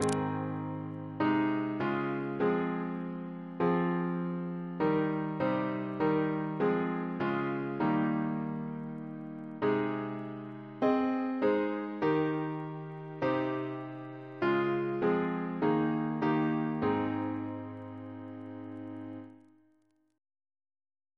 Double chant in A♭ Composer: George Thalben-Ball (1896-1987), Organist of the Temple Church Reference psalters: ACP: 355